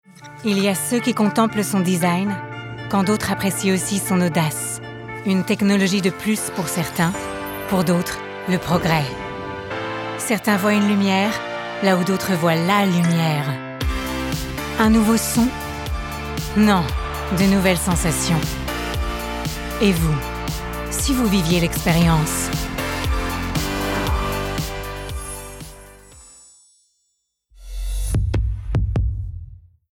Voix off
pub Audi